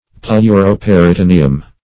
Pleuroperitoneum \Pleu`ro*per`i*to*ne"um\, n. [Pleuro- +